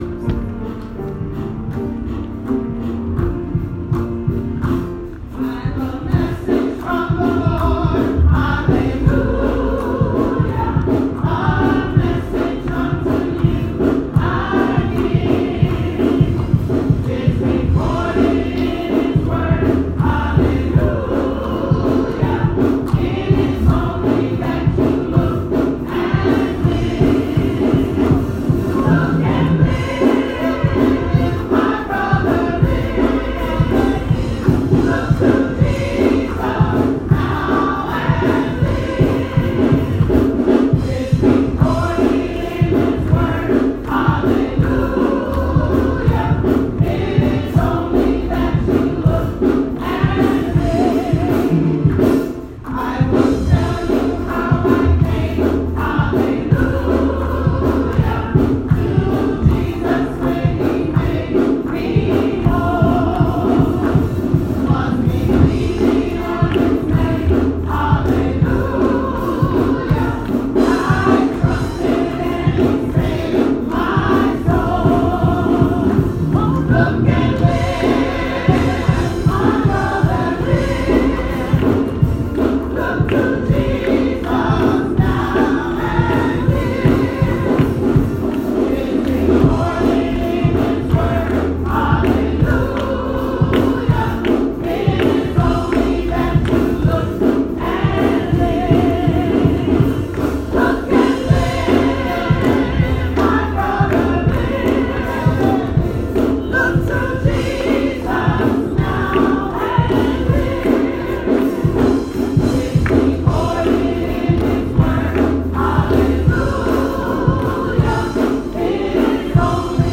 Look and Live (Audio) –Congregational Hymn, (pre-recorded)
Choir-Look-and-Live.m4a